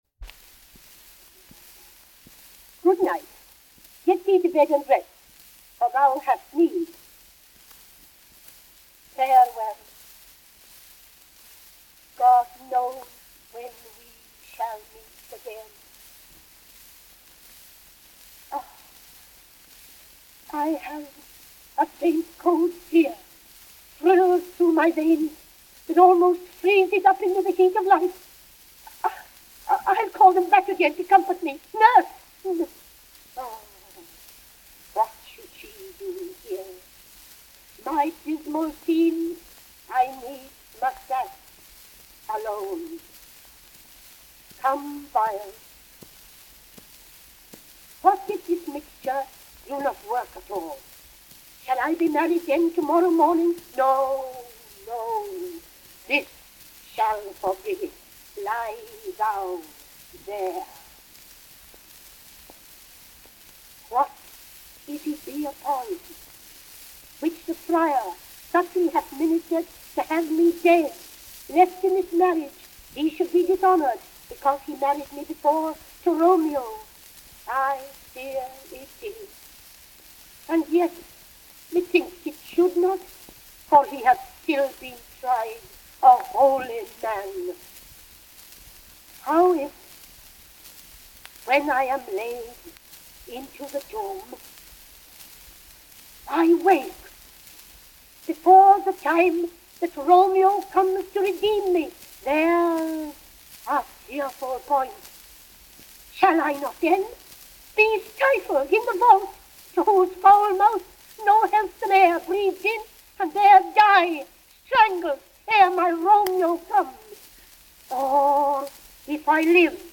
Recorded and broadcast by The BBC - October 21, 1951.